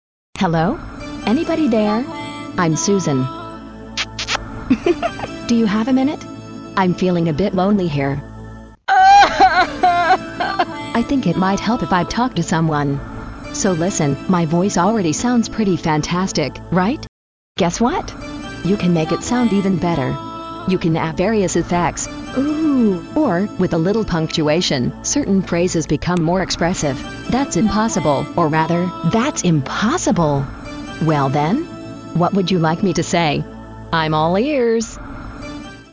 "Loquendo"のデモページにて、英文のテキストから人工音声を出力することが出来ます。
"Loquendo"の人工音声の質は凄いです。
デモ音声
• 生成される音声の質が良いので、耳が疲れない。
（例）笑い声："item=Laugh"  キスの音："item=Kiss"
• "!"を語尾に付けることにより、文を大げさに発音してくれる。